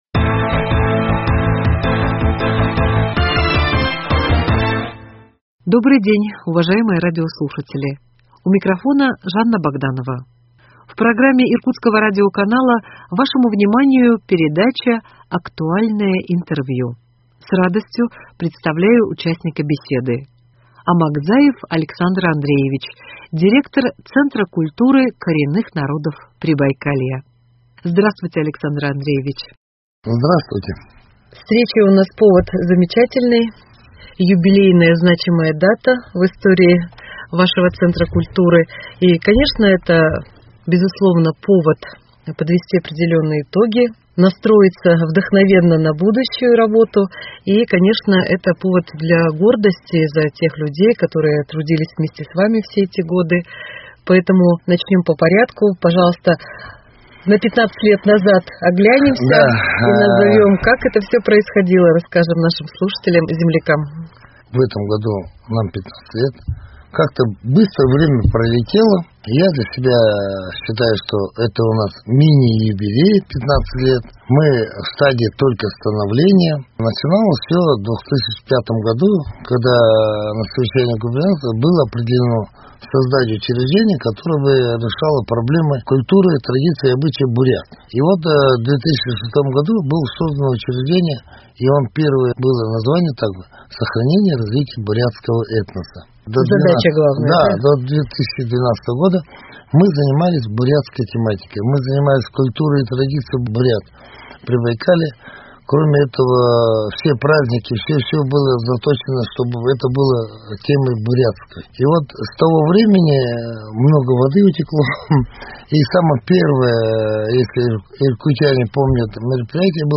Актуальное интервью: Центр коренных народов Прибайкалья 14.10.2021